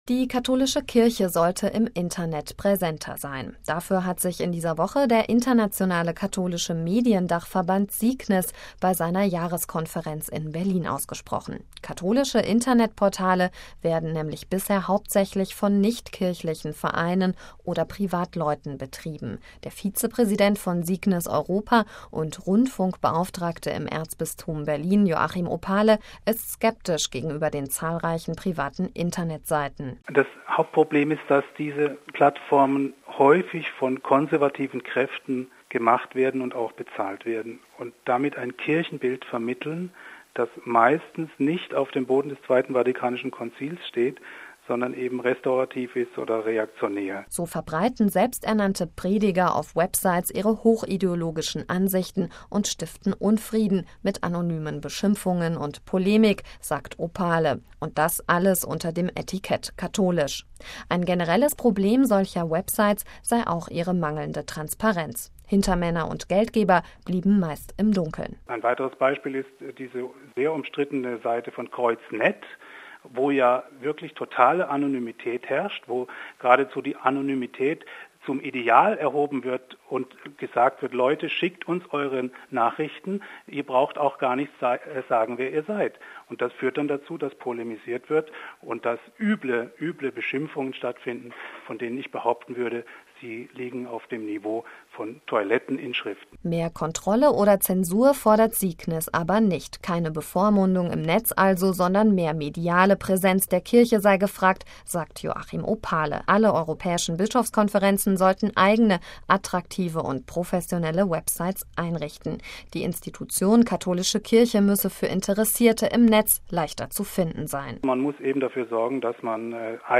Ein Beitrag über Für und Wider